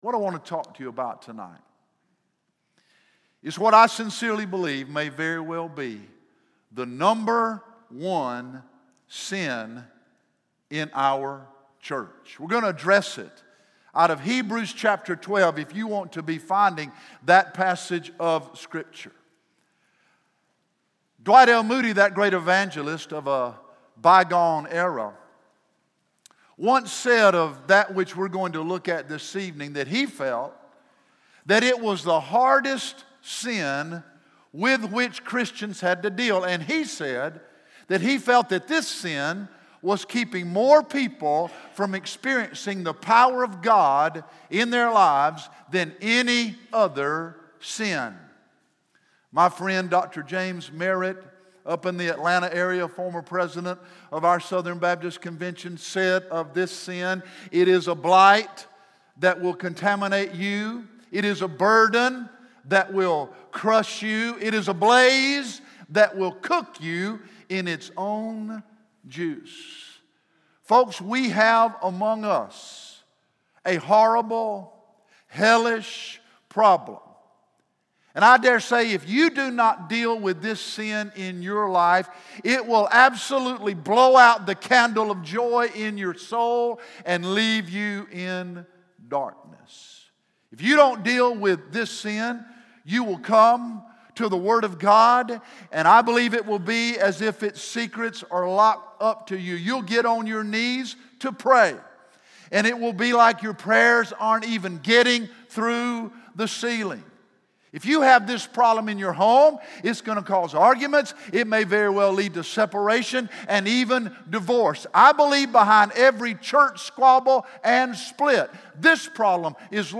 From the evening worship service on Sunday, September 9, 2018